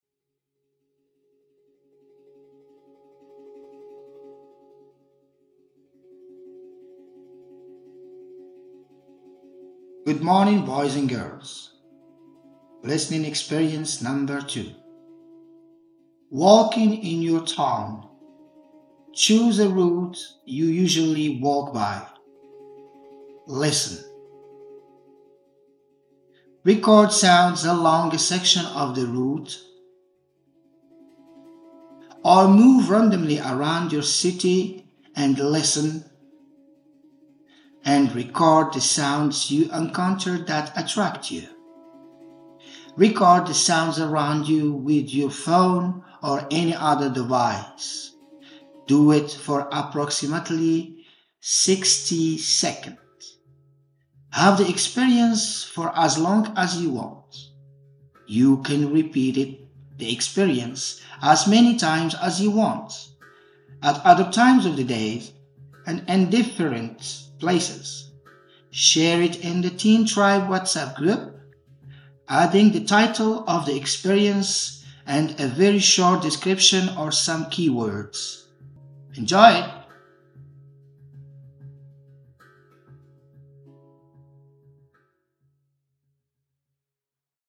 • Record the sounds with your cell phone (around 60 seconds per track).
Record sounds along a section of the route or move randomly around your city and listen and record the sounds that attract you.